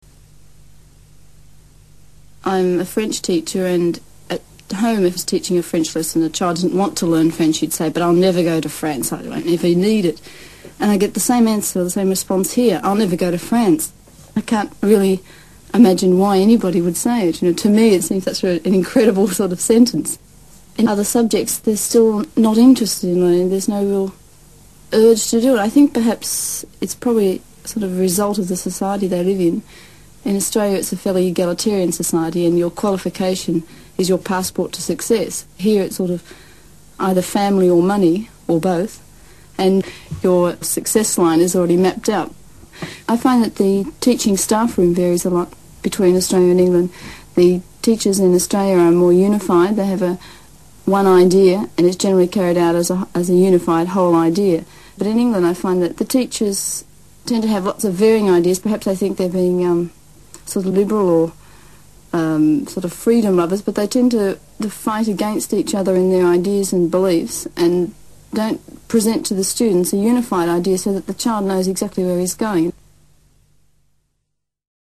An Australian Accent
An Australian French teacher from Sydney
Esta expresión hace que el enunciado de su opinión parezca más dubitativo y menos categórico.
-   /a:/ suena más parecido a /æ/ como en France, answer, passport
/eə/ suena más parecido a  /e/ como en egalitaria, varies
-   /ı/ a veces suena como /ə/ en sílabas átonas need it, imagine
-   /t/ suena más parecido a  /d/ entre vocales como en but I'll, go to, out as
AUSTRALIA-Sydney.mp3